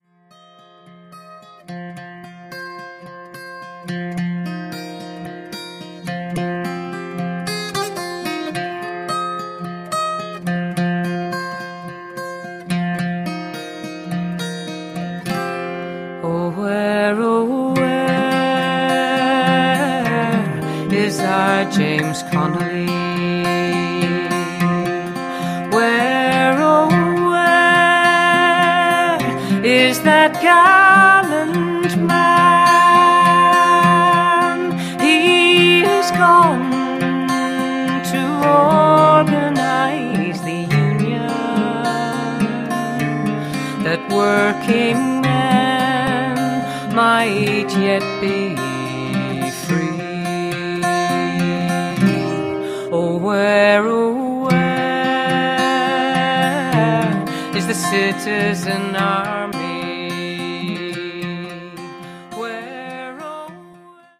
bouzouki sounds out the sadness of the events of 1916